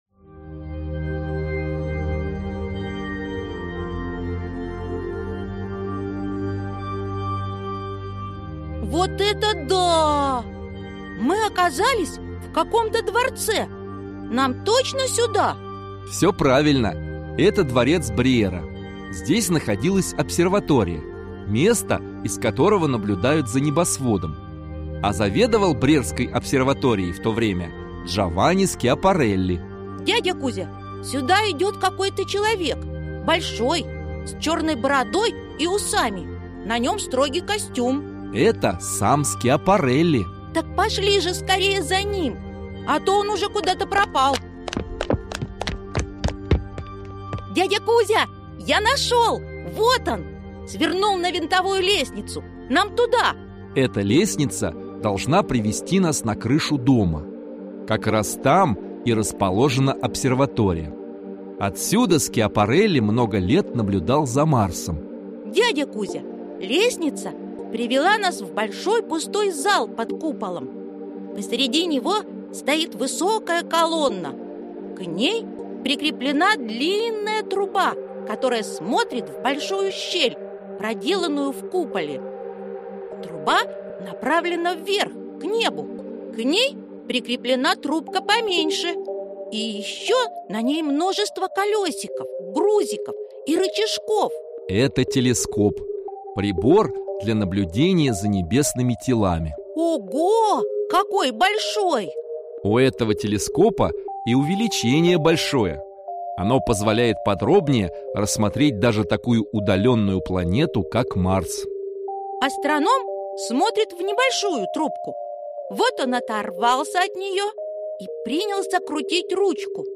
Aудиокнига Полет на Марс